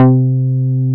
R MOOG C4MF.wav